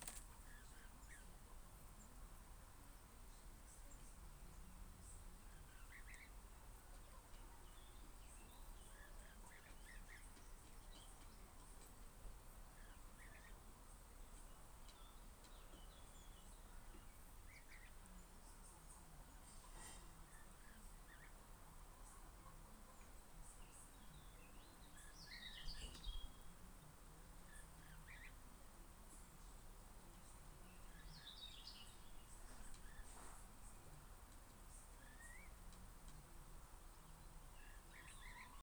Bišu dzenis, Merops apiaster
StatussDzirdēta balss, saucieni